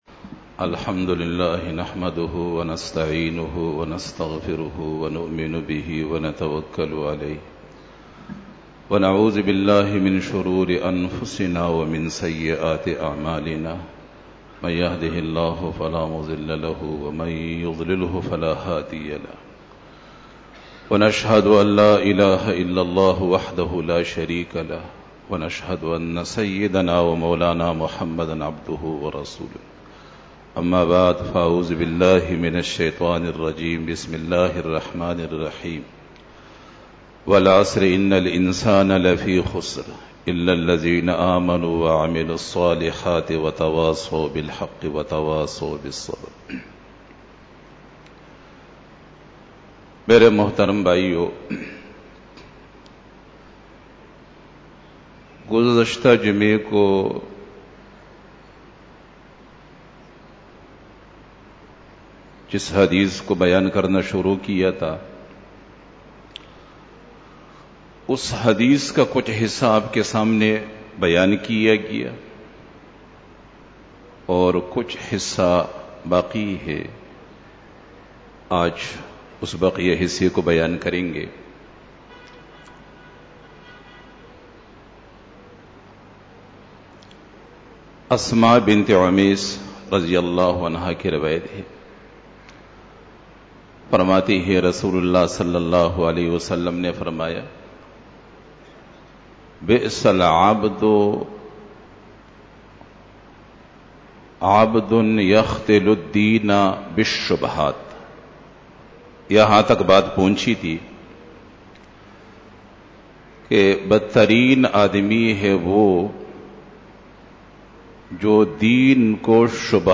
17 BAYAN E JUMA TUL MUBARAK (27 April 2018) (10 Shaban 1439H)
Khitab-e-Jummah 2018